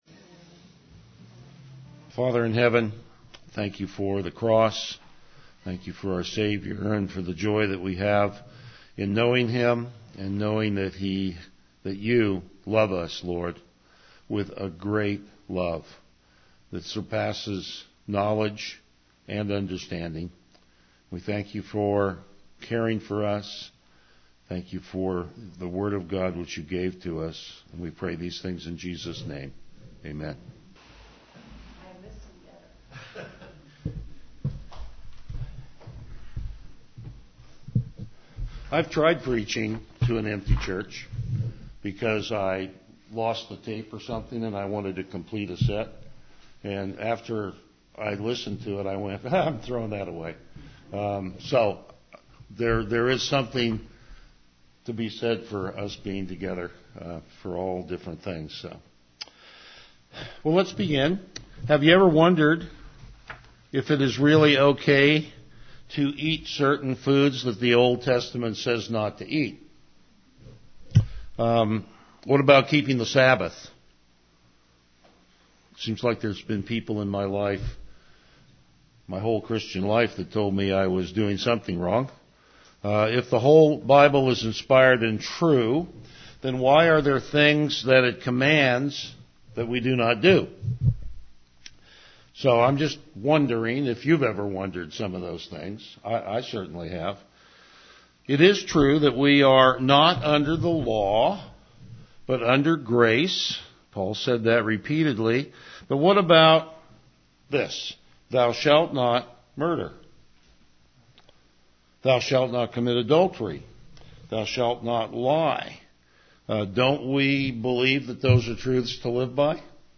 Passage: Hebrews 5:1-10 Service Type: Morning Worship
Verse By Verse Exposition